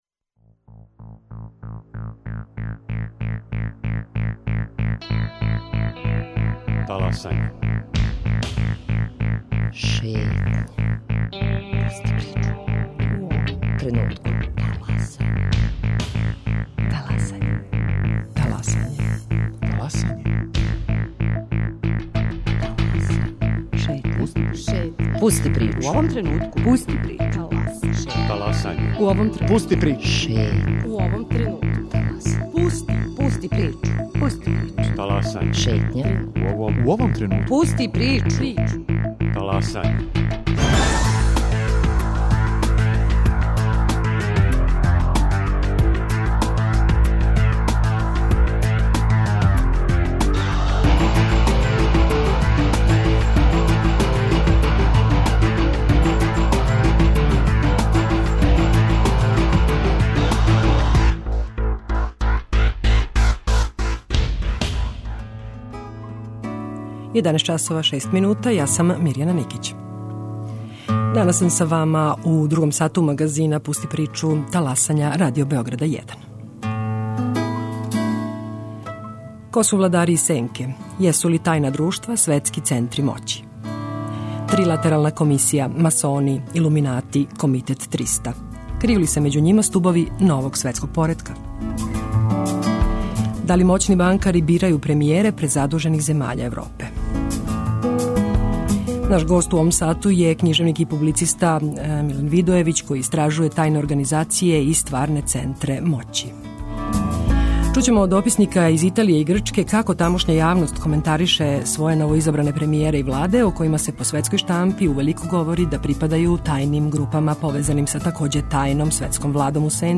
književnik i publicista.